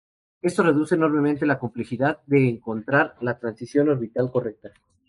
Pronounced as (IPA) /oɾbiˈtal/